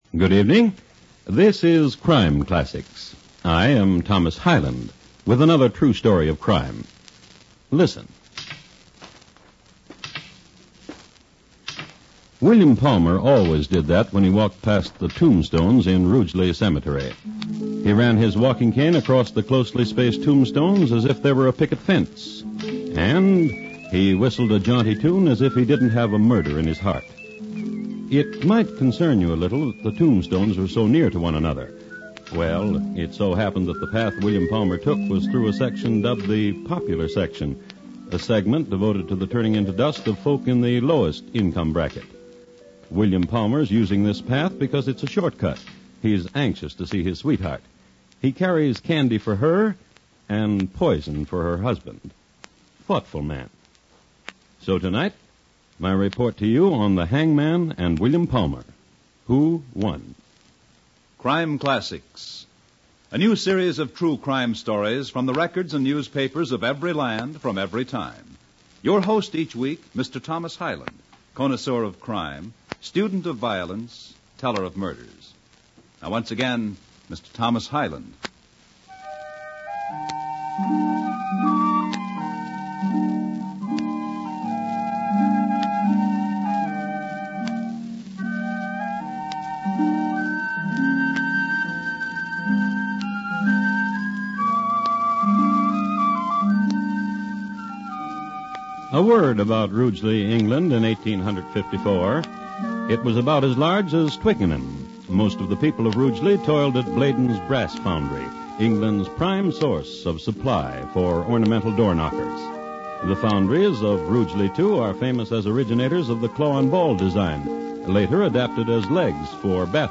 Crime Classics Radio Program, Starring Lou Merrill